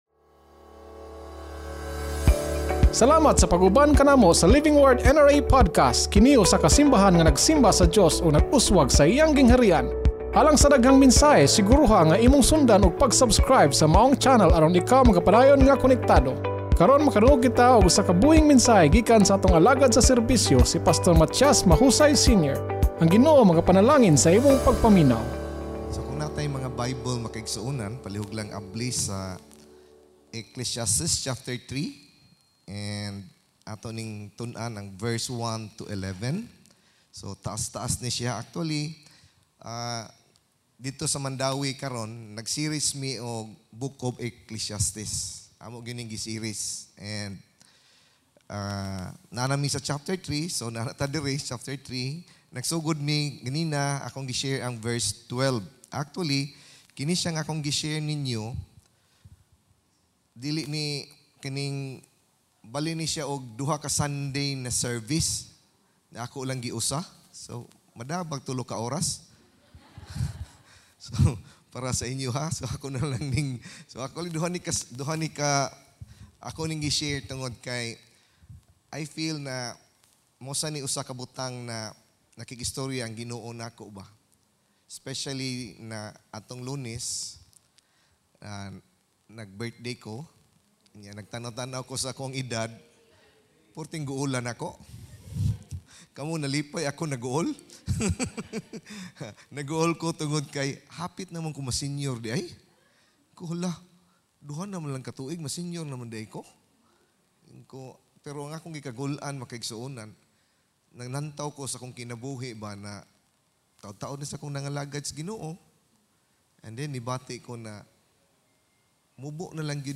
Sermon Title: NO TIME TO DIE Scripture Text: ECCLESSIASTES 3:1-11 Sermon Notes: Ecclesiastes 3:1 There is an appointed time for everything.